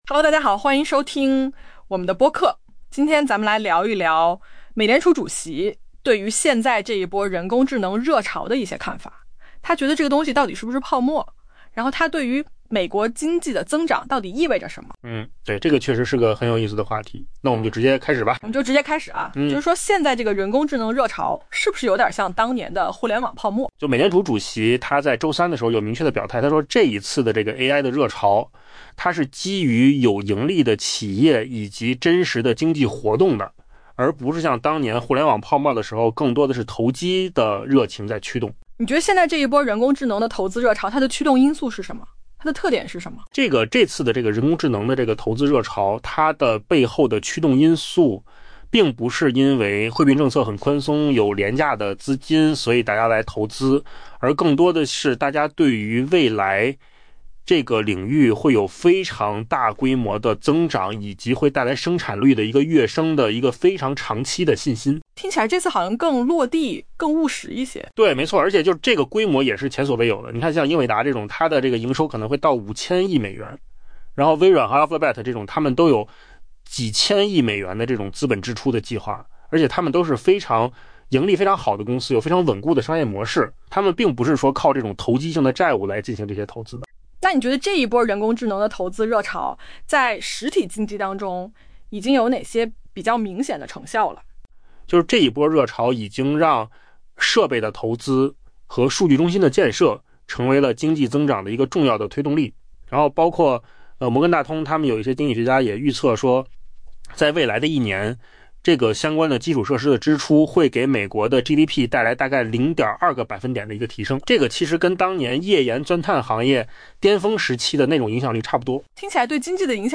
AI 播客：换个方式听新闻 下载 mp3 音频由扣子空间生成 当地时间周三，美联储主席鲍威尔明确表示， 当前的人工智能热潮并非另一场互联网泡沫 。